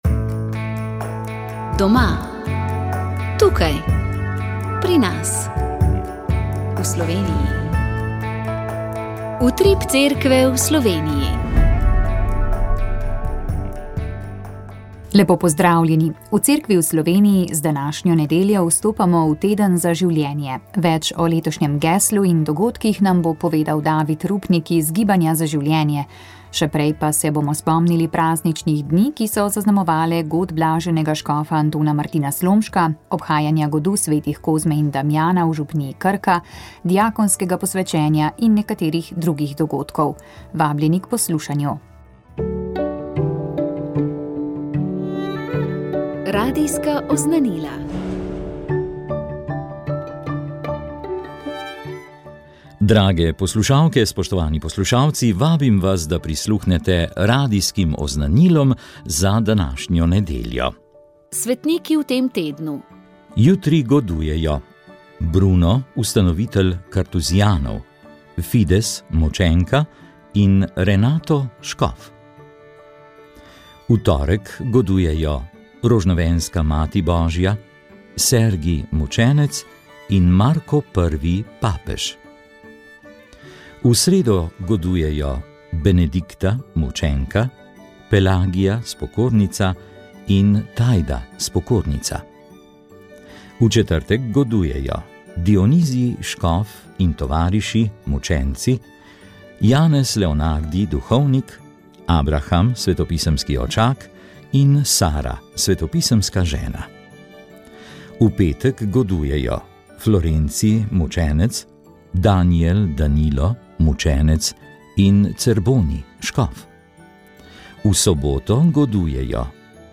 Rožni venec
Molile so redovnice - Hčere Marije Pomočnice.